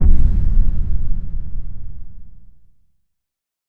Low End 23.wav